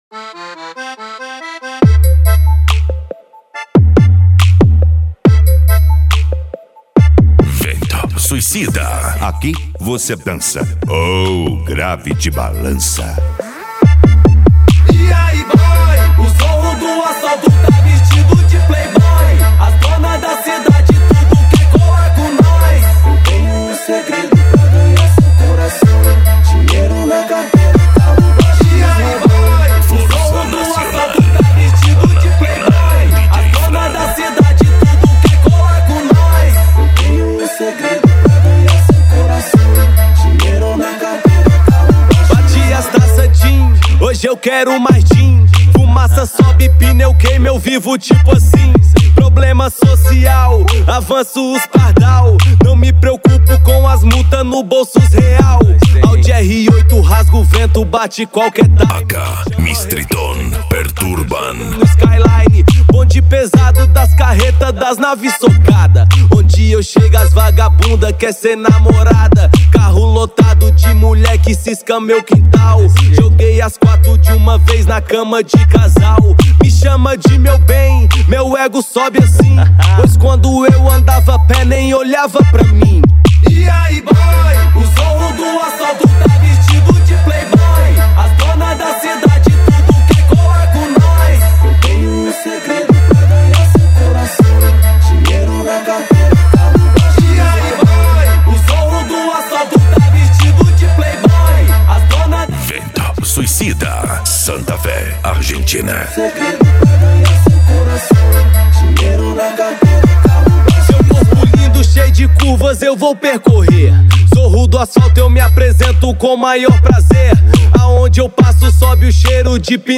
Bass
PANCADÃO
Psy Trance
Racha De Som